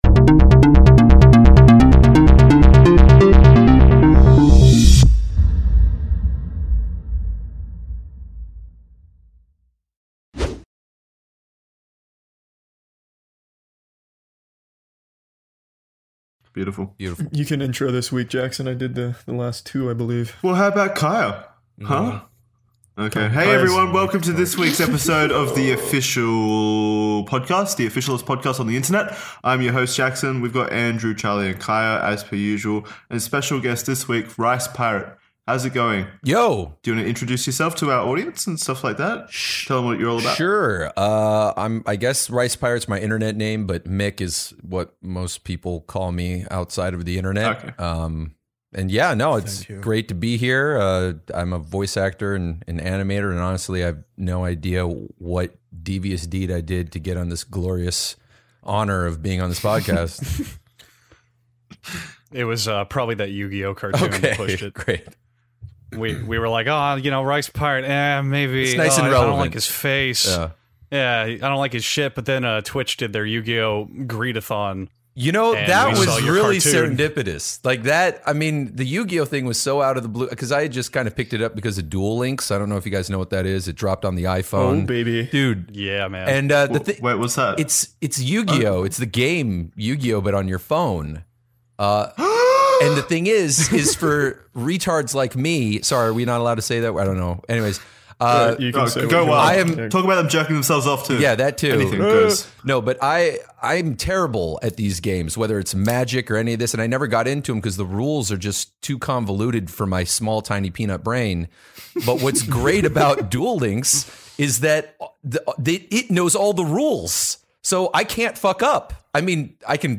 Five close man friends gather around to sound the alarms.